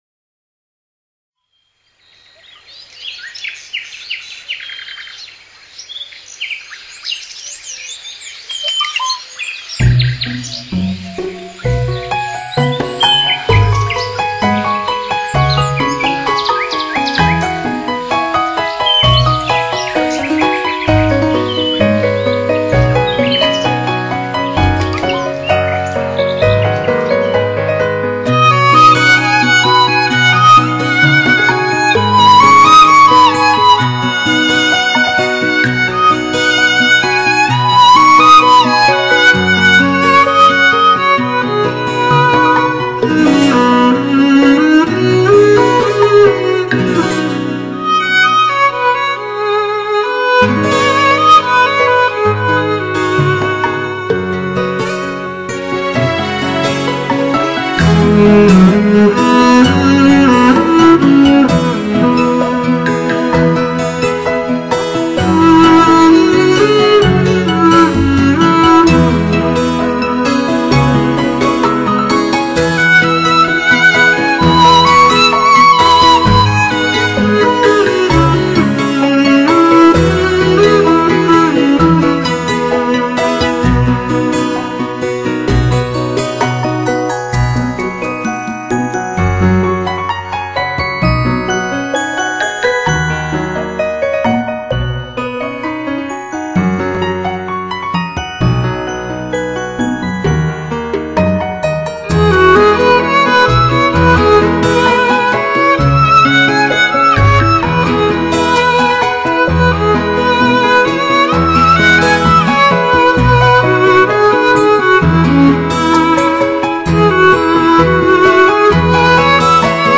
那灵动的水晶琴声、宁静祥和的和声，以及欢快的
鸟鸣及溪水的潺潺奔流声，使新世纪音乐及大自然